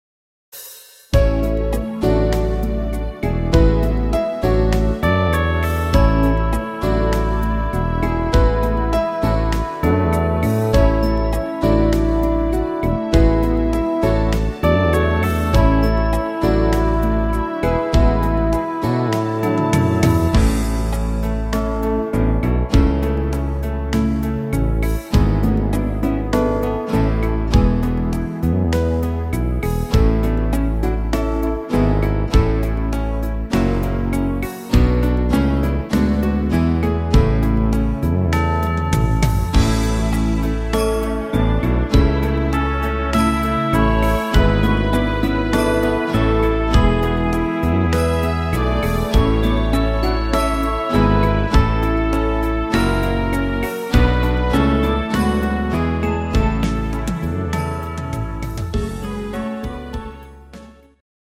instr. Flöte